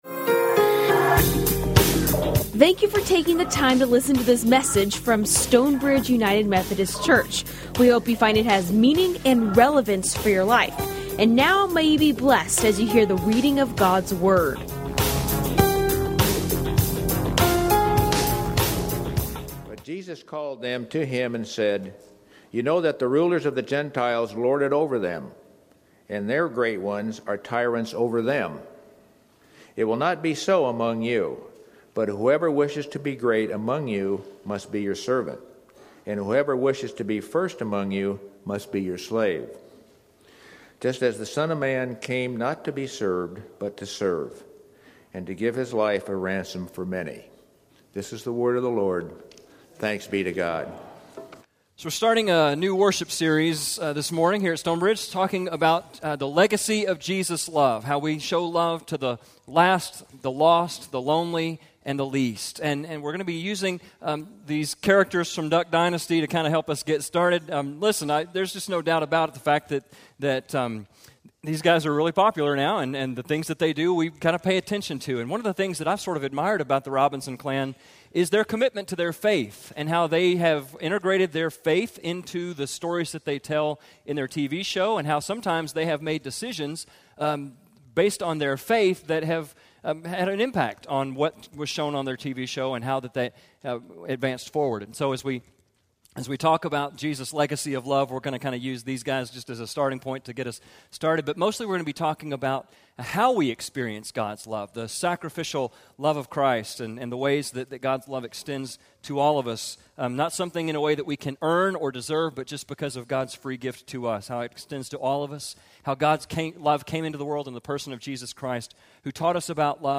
Recorded live at Stonebridge United Methodist Church in McKinney, TX.